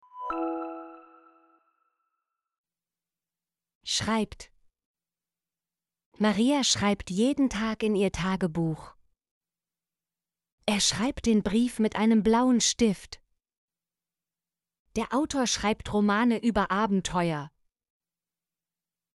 schreibt - Example Sentences & Pronunciation, German Frequency List